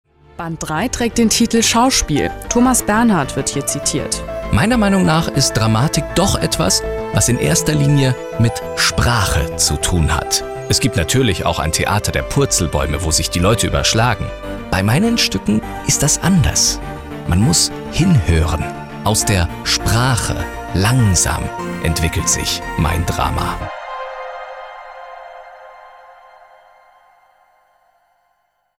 Sehr vielseitige und warme Stimme.
Kein Dialekt
Sprechprobe: eLearning (Muttersprache):
german voice over artist